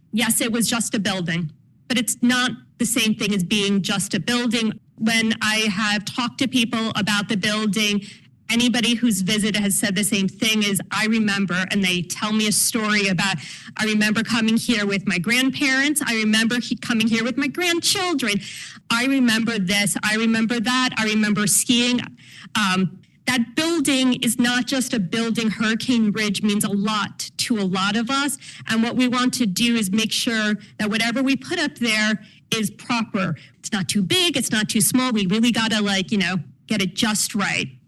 Port Angeles – Olympic National Park Superintendent Sula Jacobs presented an update on the park at Tuesday’s City council meeting, and spoke primarily about what to expect at next week’s free public event at Field Arts and Events Hall in Port Angeles.